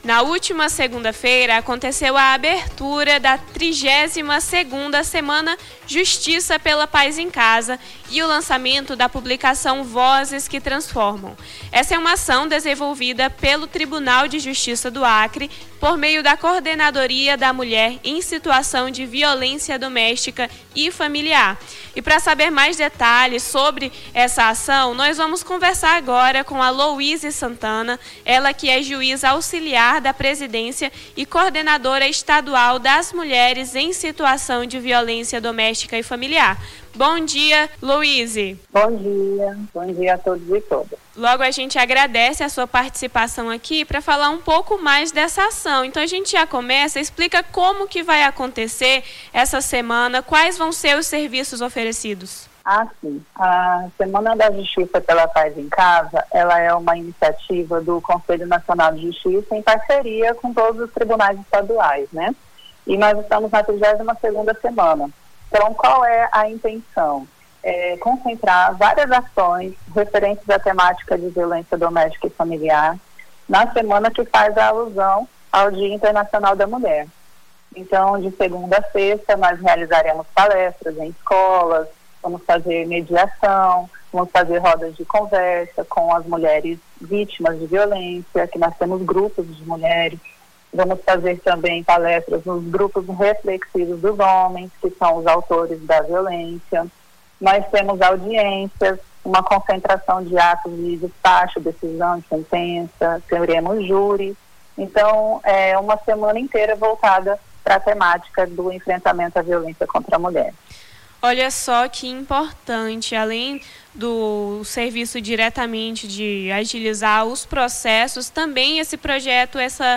Na manhã desta terça-feira, 10, conversamos com Louise Santana, juíza auxiliar da presidência e coordenadora estadual das mulheres em situação de violência doméstica e familiar sobre a programação da 32ª Semana Justiça pela Paz em Casa, lançamento da Cartilha Vozes que Transformam e o combate da violência contra a mulher.